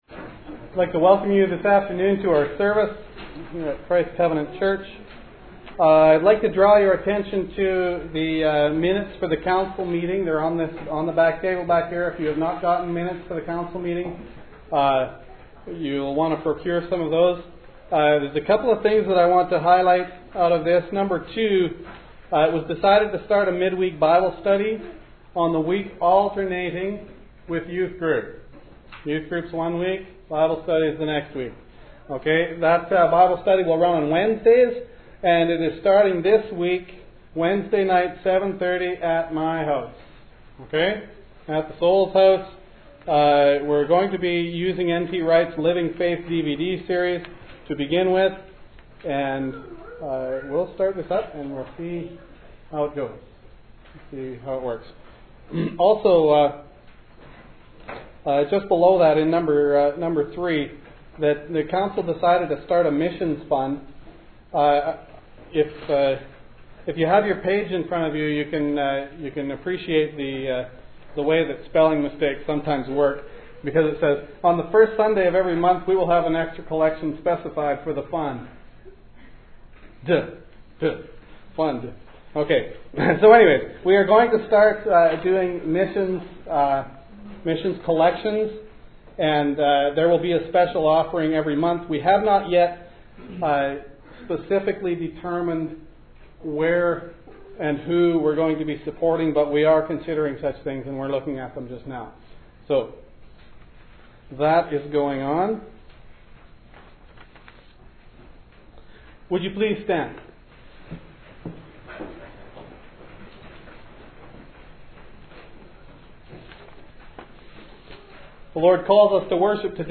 He references Psalm 1 to highlight the path towards blessedness that leads away from the world's philosophy of life. The sermon also includes a scripture reading from Ezekiel 33, which discusses the role of a watchman and the consequences of not heeding warnings.